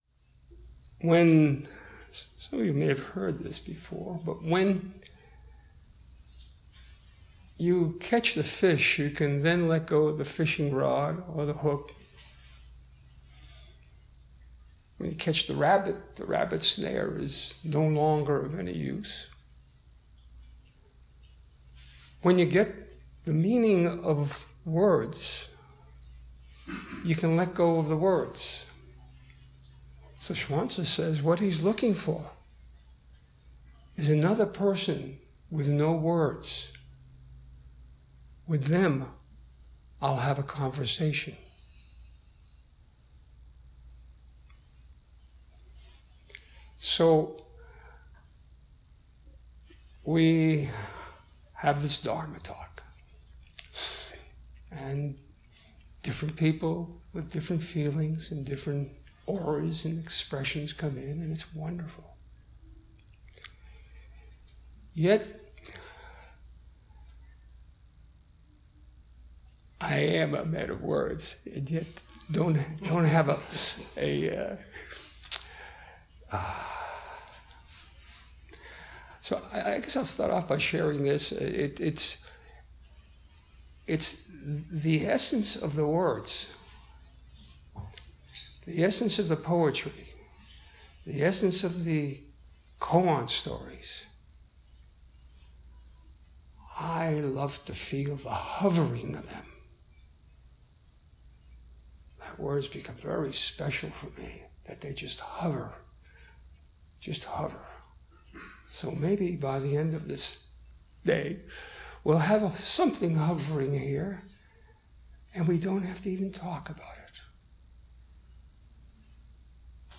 Dharma Talk
February, 2018 Southern Palm Zen Group